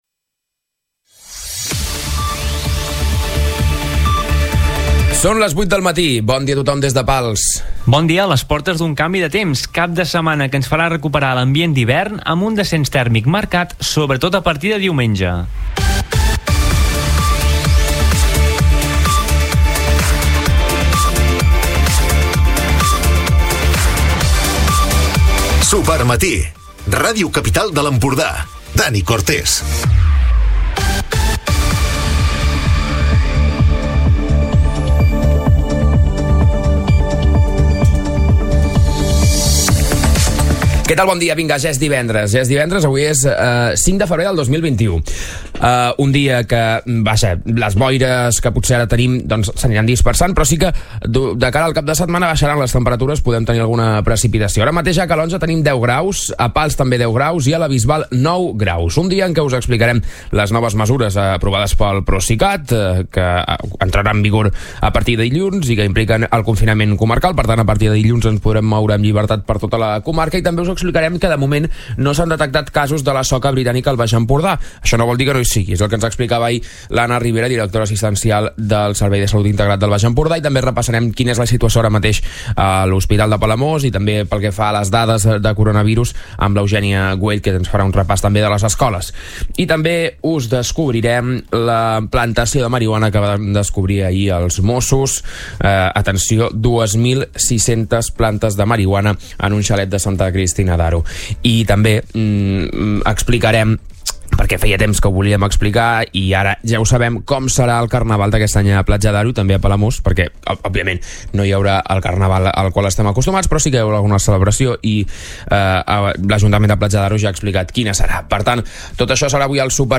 Recupera l'informatiu d'aquest divendres